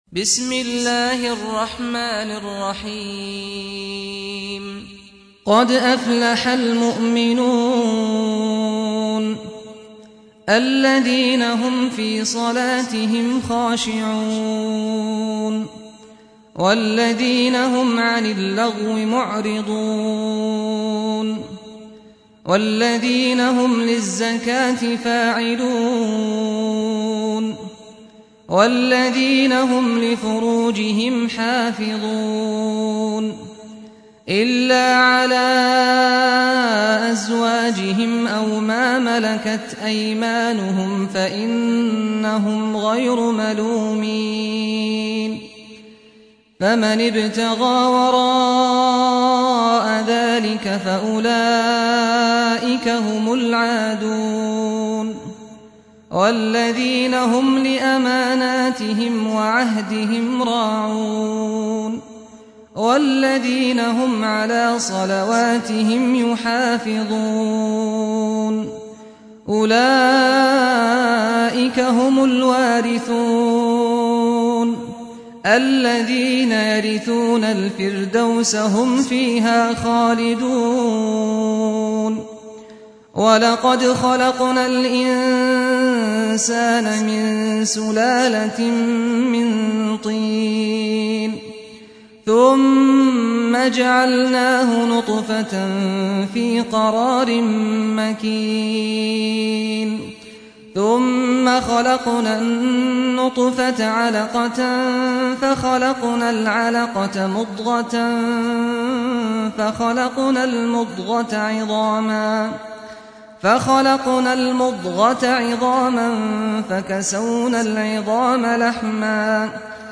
استمع أو حمل سُورَةُ المُؤۡمِنُونَ بصوت الشيخ سعد الغامدي بجودة عالية MP3.
سُورَةُ المُؤۡمِنُونَ بصوت الشيخ سعد الغامدي